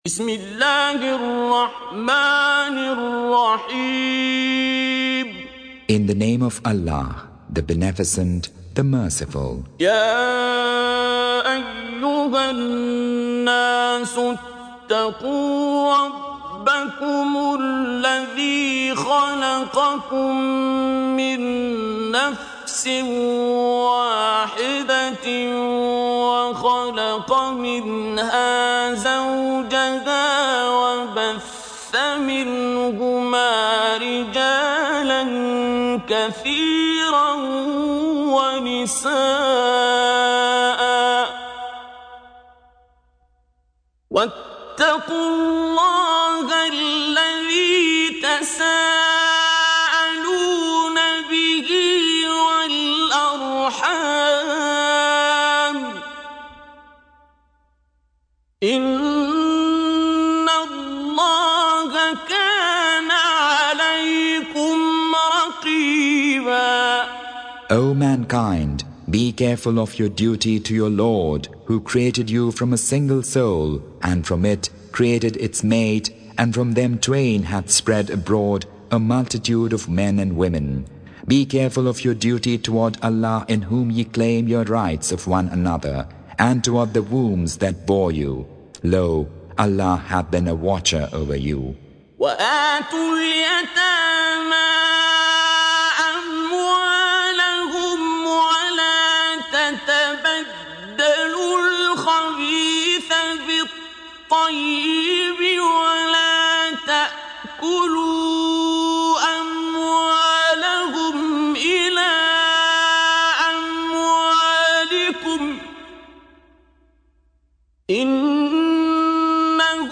Recitation
Surah Repeating تكرار السورة Download Surah حمّل السورة Reciting Mutarjamah Translation Audio for 4. Surah An-Nis�' سورة النساء N.B *Surah Includes Al-Basmalah Reciters Sequents تتابع التلاوات Reciters Repeats تكرار التلاوات